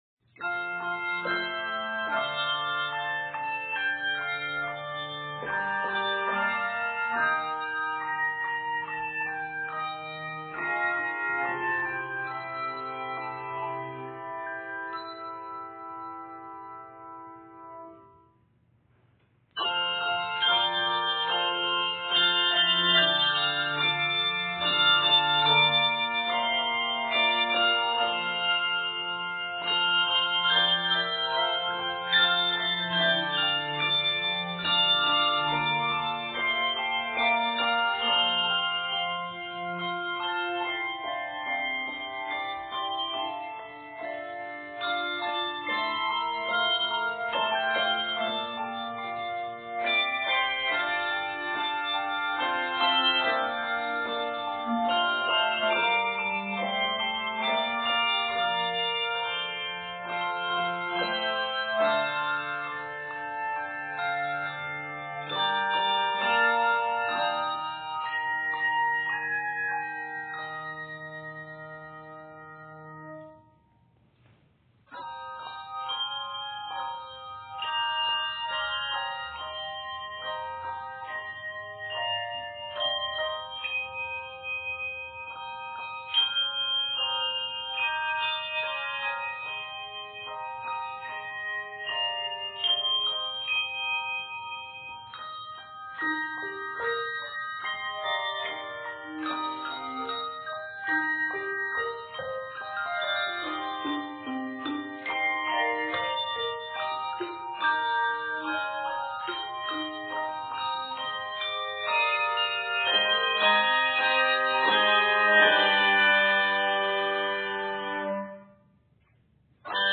3 to 5 octaves of handbells